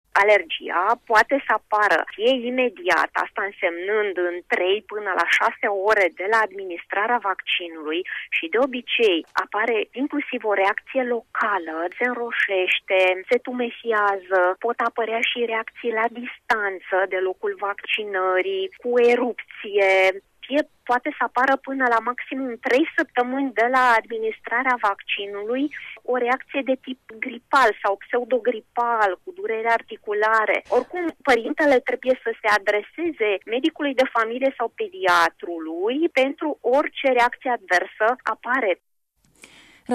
Medicul alergolog